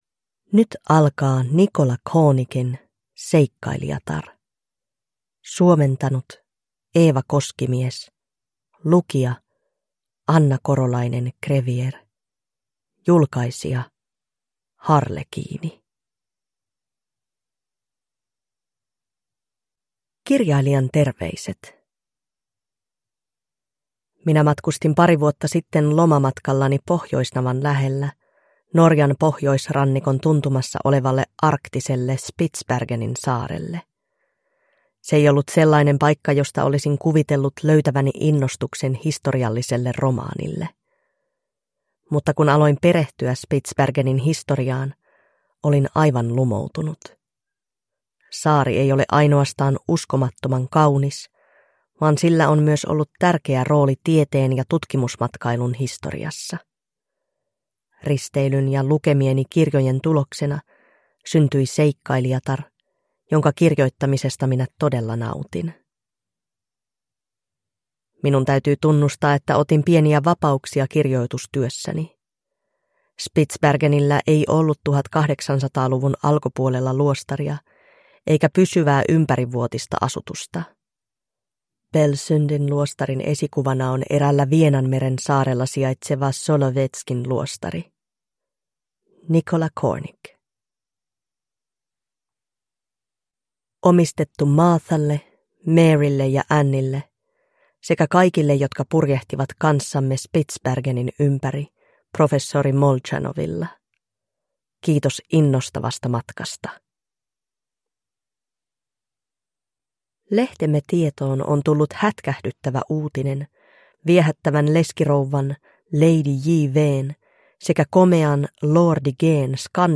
Seikkailijatar (ljudbok) av Nicola Cornick